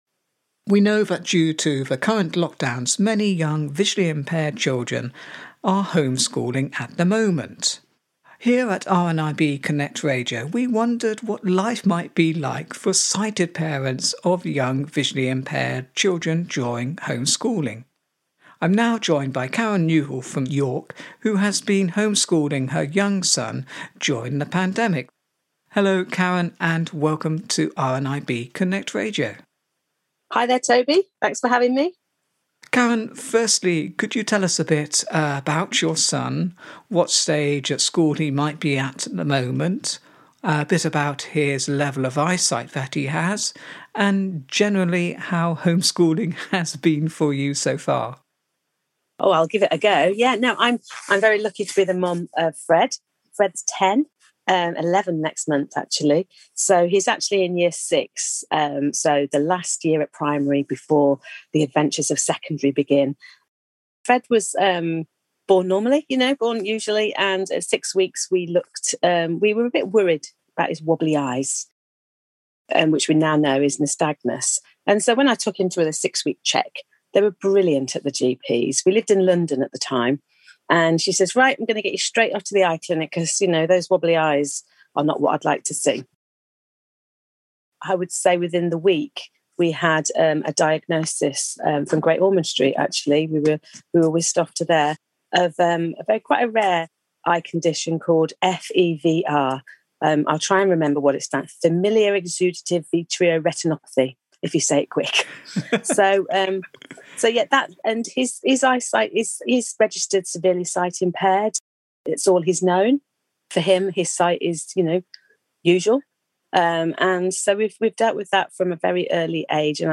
Due to all the Covid lockdowns many visually impaired young people have been and are still being schooled at home by their parents. Here at RNIB Connect Radio we wondered what home schooling might be like for a sighted parent of a visually impaired young person during lockdown.